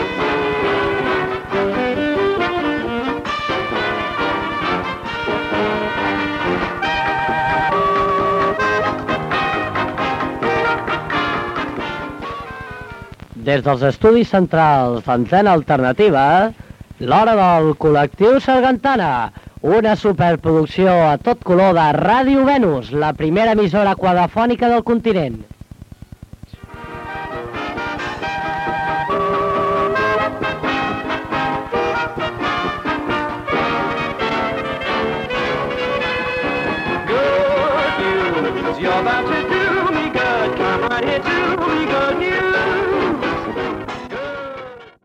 Identificació del programa de Ràdio Venus, dins d'Antena Alternativa.